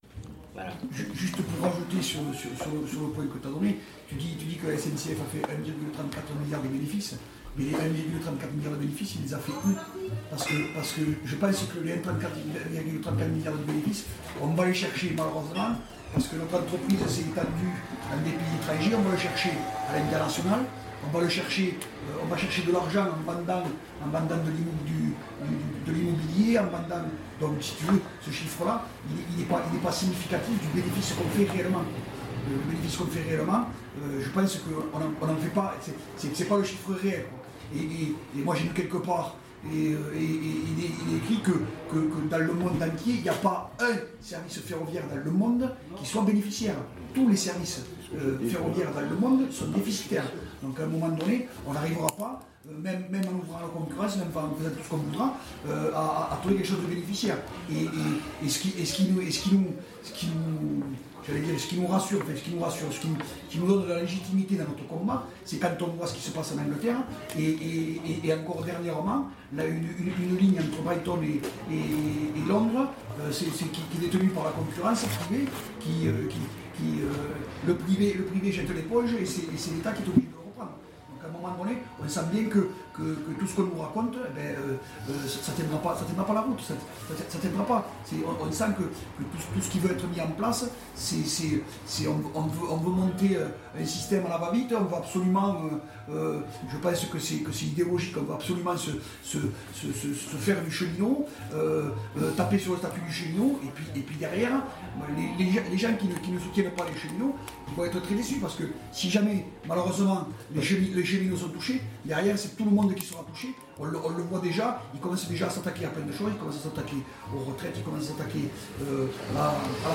Le lieu est vivant (le bruit de fond en témoigne), on bosse à Itinéraire-Bis. La musique est bonne, on devine du blues par moment.
Donner la parole et donner le temps d’expliquer ; il fautdonc écouter attentivement les faits énoncés durant cet entretien sur le saccage du fret ferroviaire et comment on tue le rail pour favoriser le camion sans rien démontrer de probant quant à l’économie ; mais en abimant considérablement la planète.